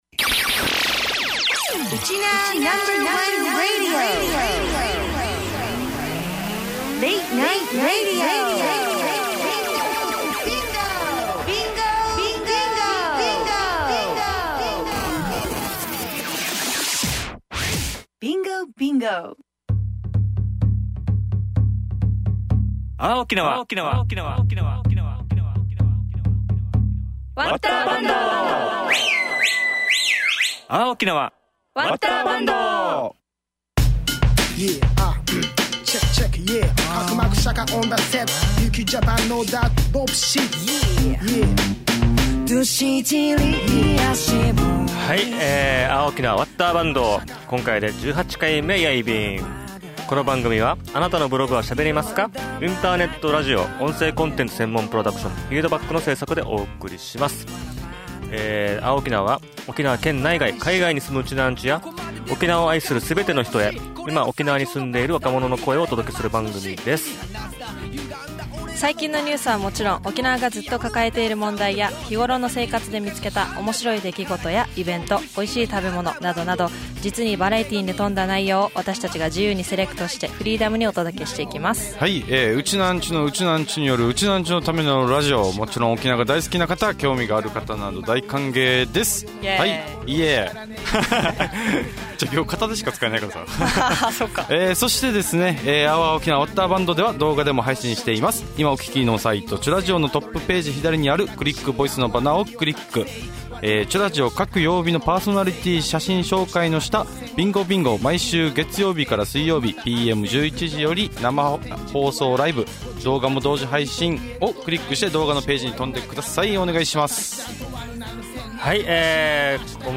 OUR OKINAWA 〜わったーばんどー！！11/3放送★沖縄ゆんたくフリートークでは「辺野古への新基地建設と県内移設に反対する県民大会」について熱くトーク